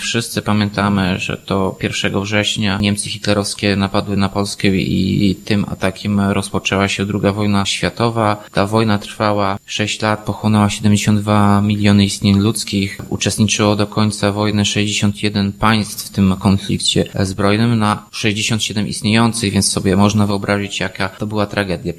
Przypomina Tomasz Andrukiewicz, prezydent Ełku.